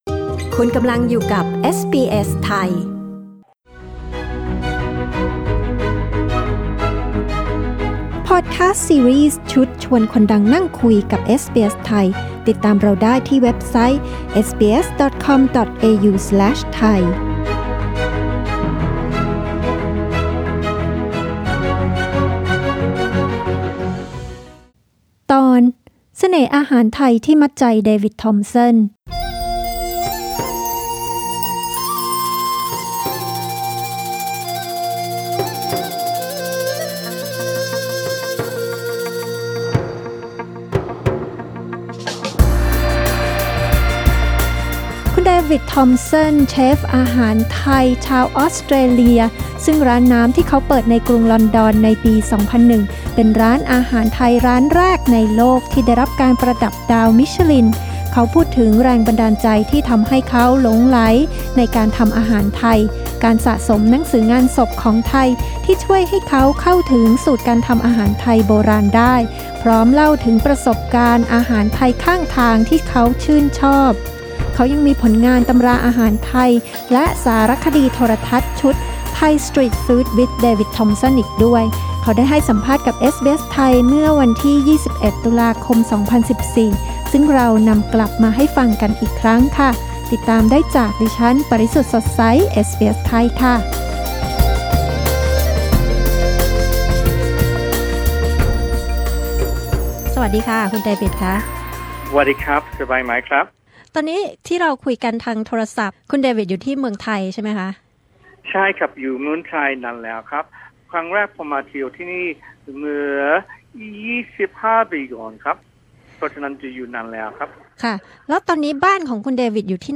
เขาได้ให้สัมภาษณ์กับเอสบีเอส ไทย เมื่อวันที่ 21 ตุลาคม 2014